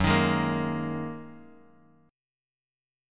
Gb6add9 chord